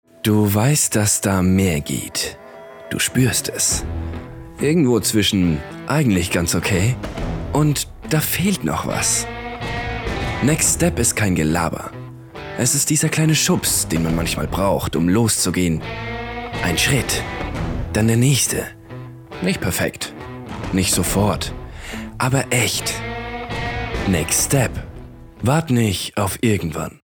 dunkel, sonor, souverän, markant
Jung (18-30)
Bayrisch
Commercial (Werbung)